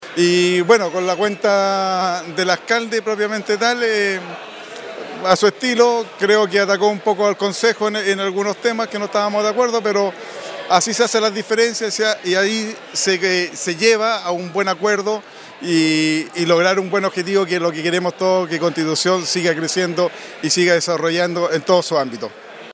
El concejal Carlos Segovia Letelier reconoció la franqueza del discurso del alcalde: